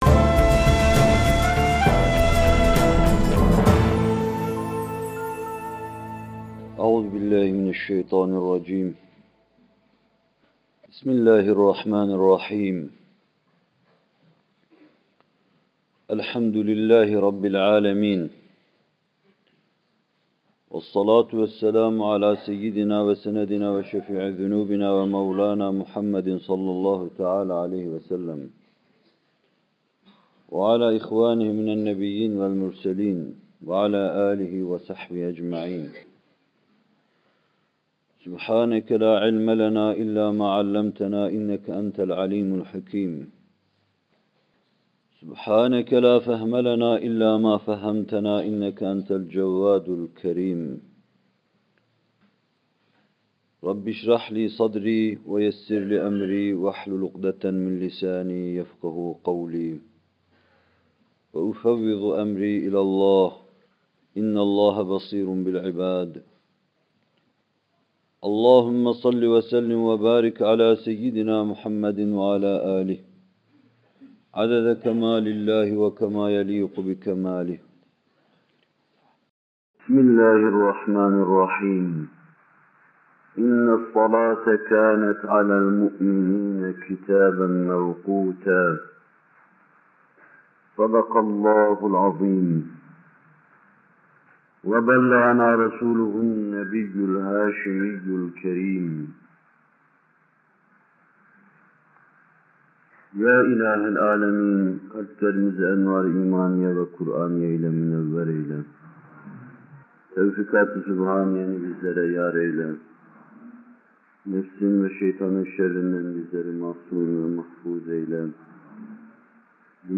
Bu bölüm Muhterem Fethullah Gülen Hocaefendi’nin 1 Eylül 1978 tarihinde Bornova/İZMİR’de vermiş olduğu “Namaz Vaazları 3” isimli vaazından alınmıştır.